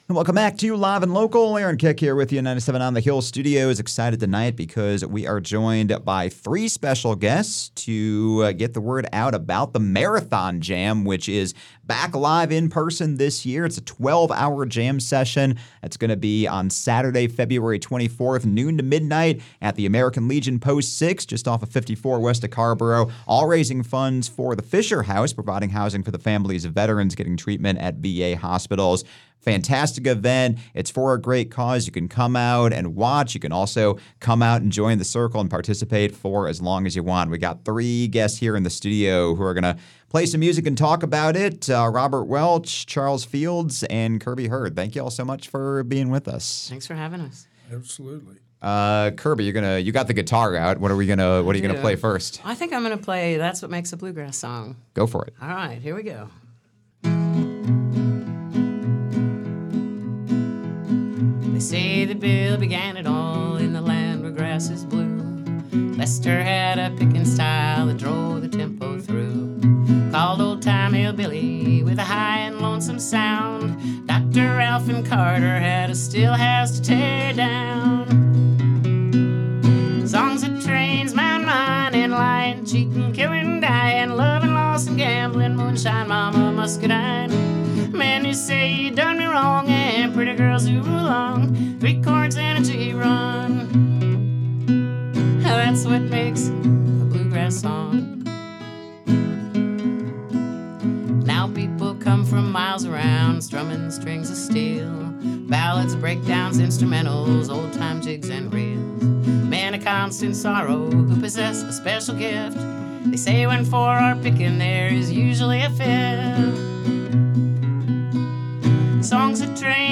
stopped by Live & Local this week to play a few songs